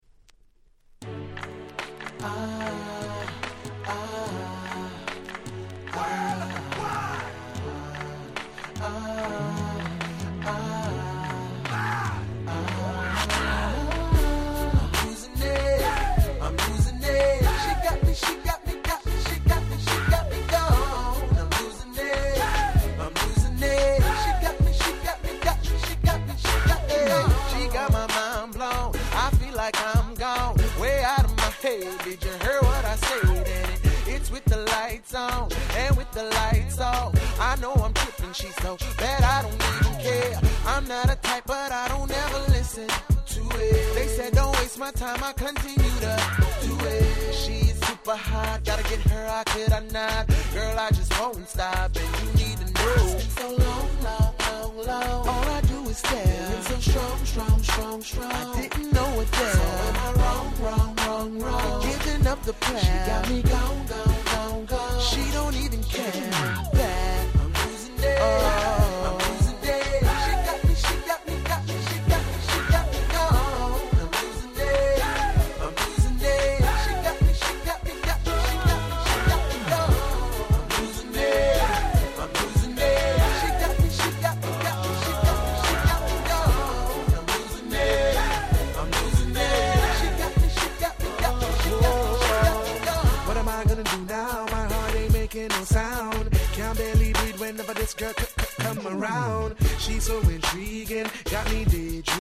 08' Nice R&B !!